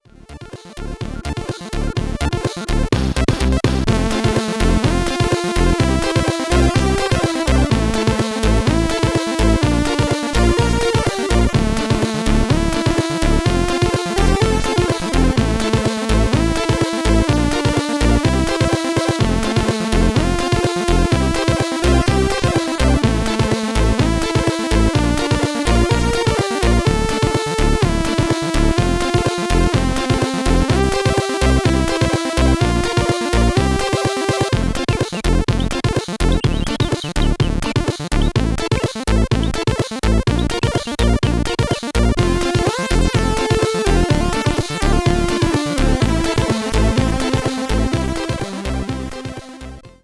How about some 8-bit chip-music?
a happy tune